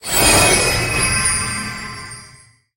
sandy_gadget_01.ogg